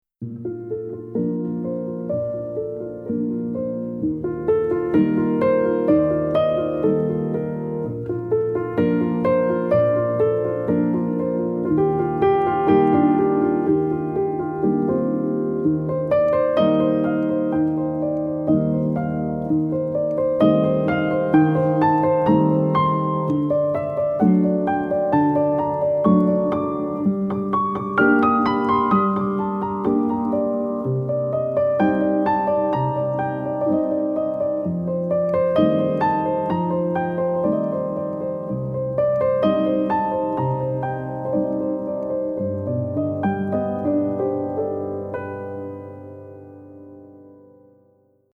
No lyrics, as this song is an instrumental.